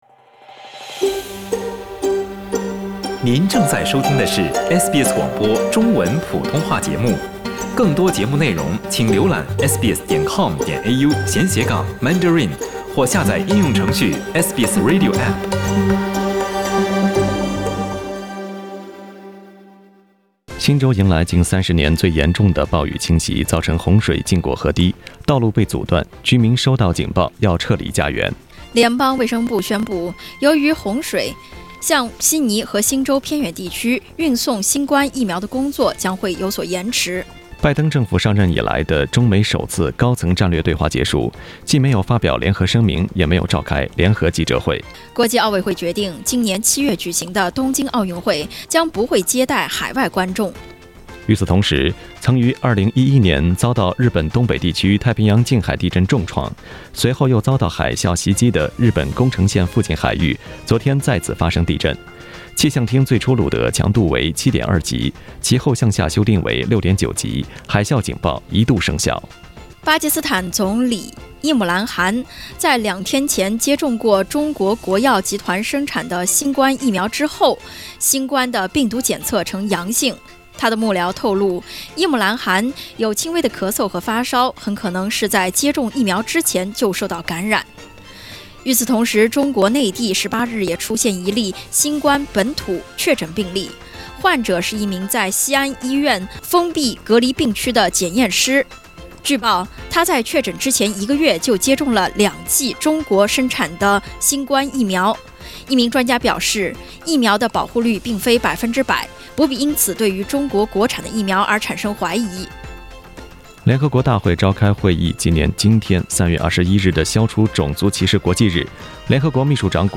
SBS早新聞（3月21日）